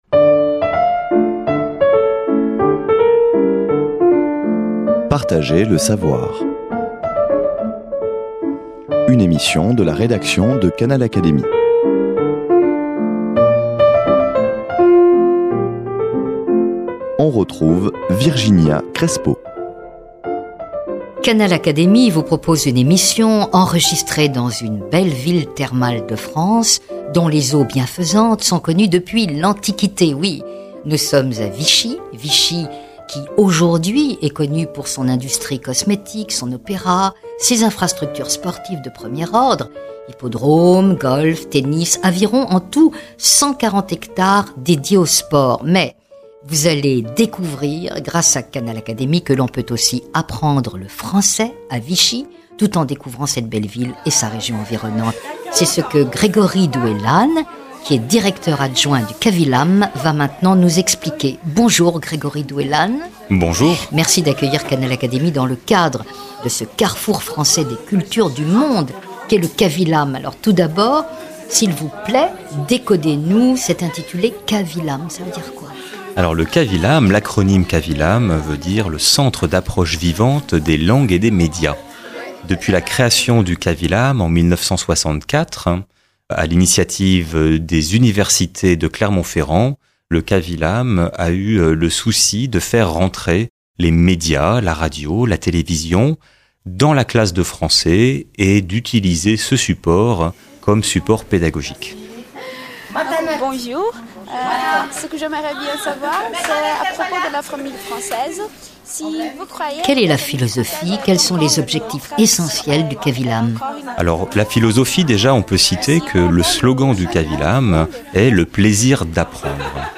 et se poursuit par l’exposé du travail fourni par 16 jeunes étudiantes de diverses nationalités